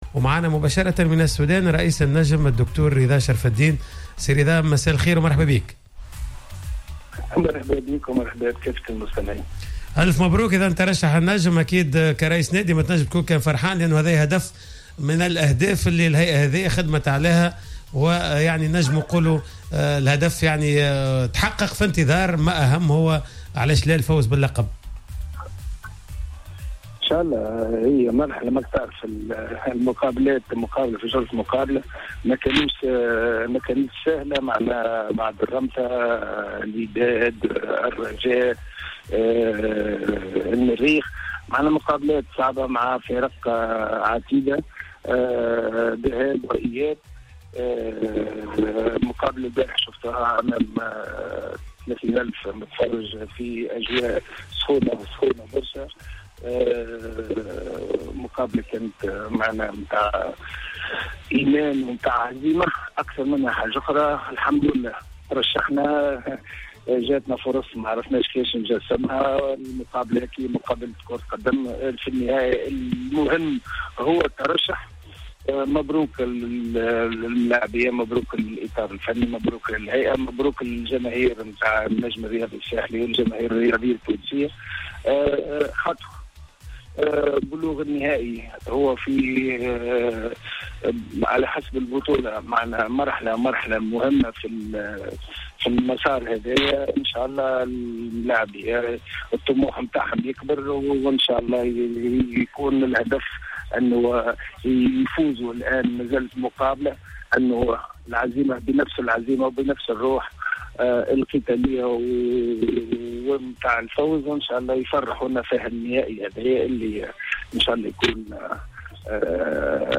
أكد رئيس النجم الرياضي الساحلي الدكتور رضا شرف الدين لدى تدخله في حصة "Planète Sport" أن بلوغ الفريق للدور النهائي من منافسات كأس زايد للأندية الأبطال يعتبر إنجازا مهما.